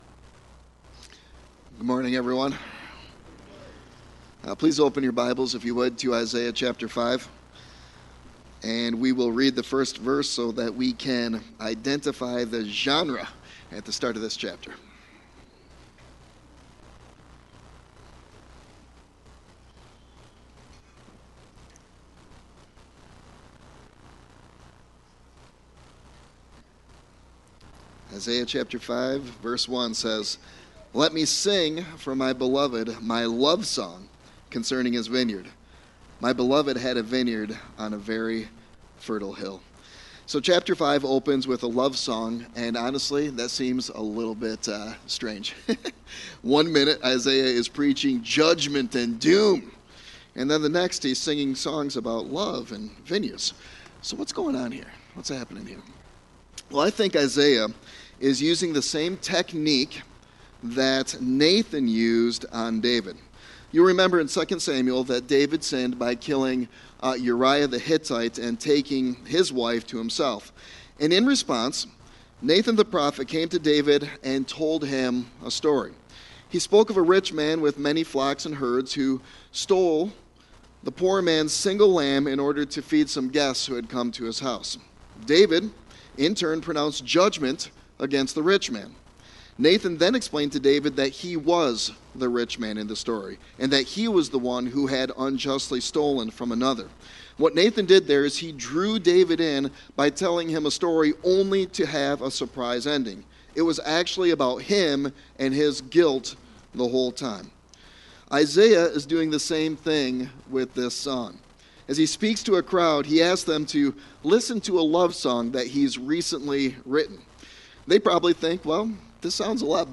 Sermon Text: Isaiah 5:1-30